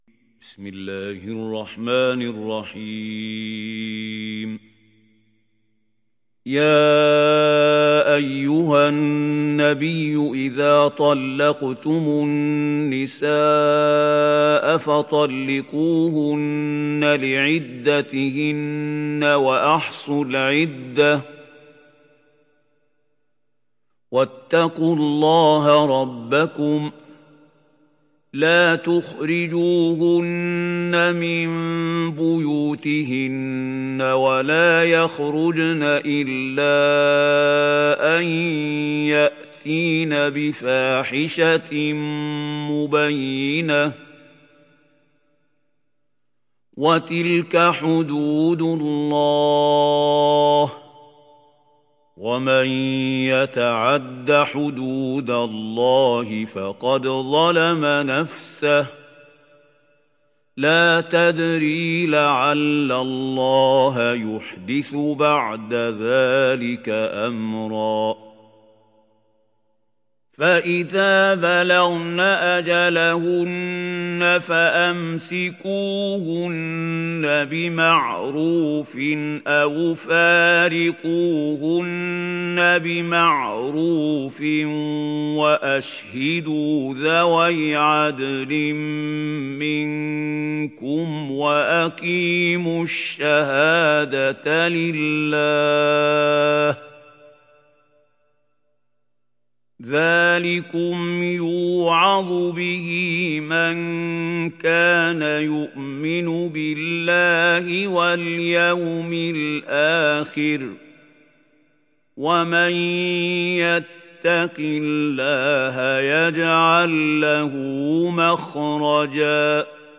دانلود سوره الطلاق mp3 محمود خليل الحصري روایت حفص از عاصم, قرآن را دانلود کنید و گوش کن mp3 ، لینک مستقیم کامل